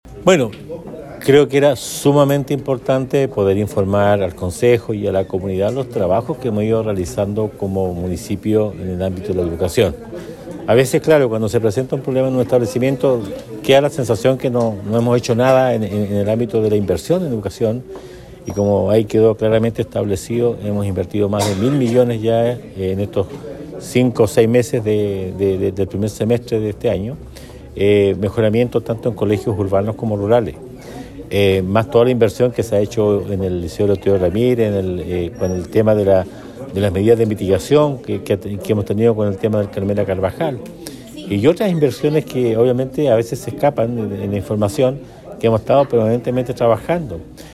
Dar a conocer esta información a la comunidad es fundamental según indicó el alcalde Emeterio Carrillo, pues de esta forma se puede observar todo el trabajo que se realiza desde la gestión municipal para mejorar la educación local.